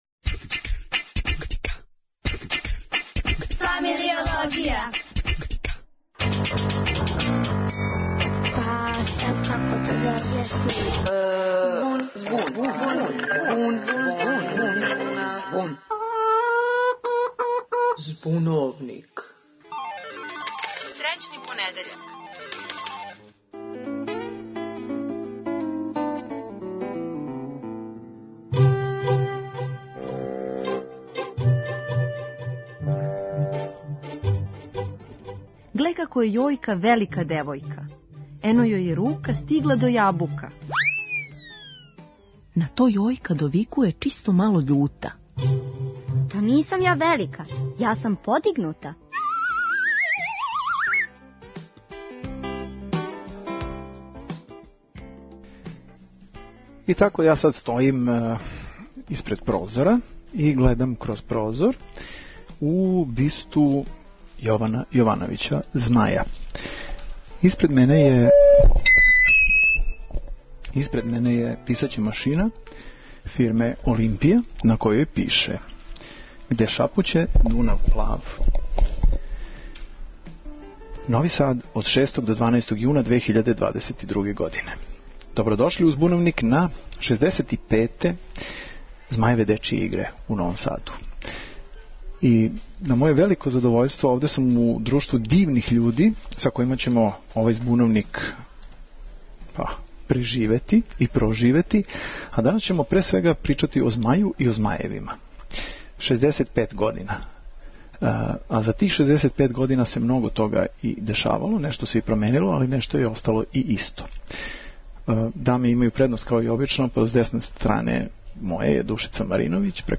Зато, и Збуновник иде Змају у походе да провери има ли неких нових змајева. Слушајте нас уживо из Новог Сада, са Змајевих дечјих игара, тамо "где шапуће Дунав плав..."